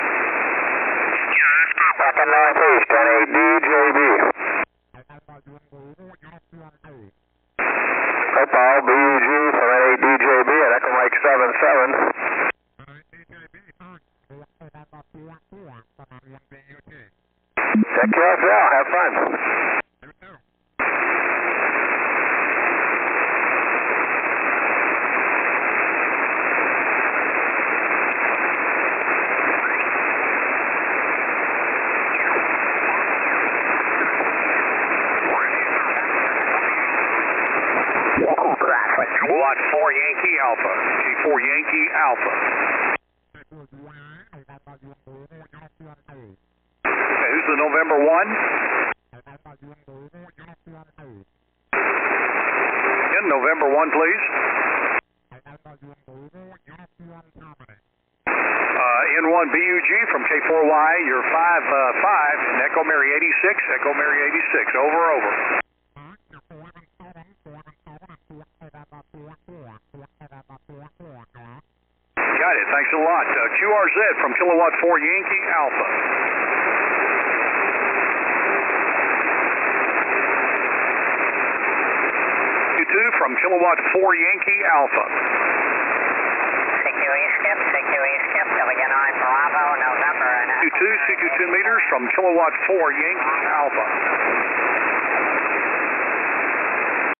I worked five stations in total, four states, four grids; best DX 1117 miles. All SSB, running 25 watts to a short 7 element yagi on my end.